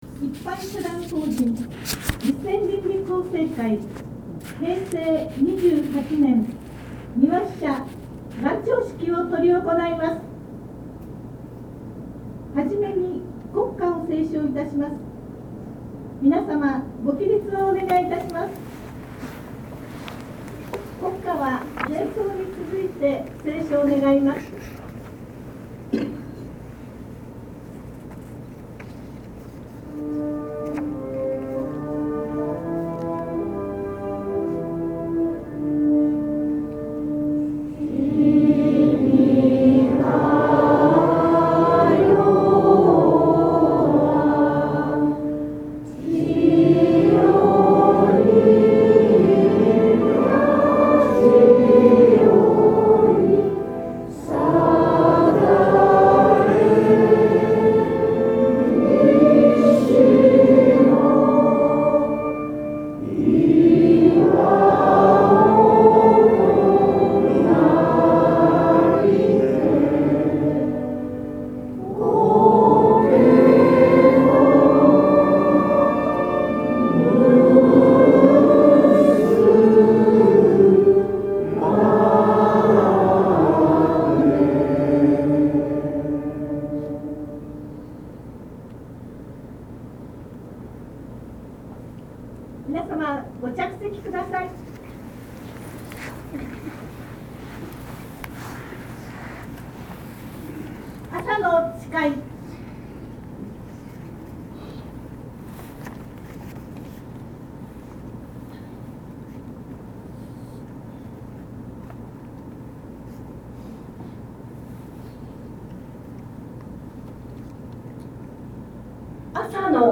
会長先生の念頭の辞は40分程度であった。話は、先年の戦後70年の話からテレビドラマである向田邦子の作品 寺内貫太郎一家の主人の頑固親父の話に及んだ。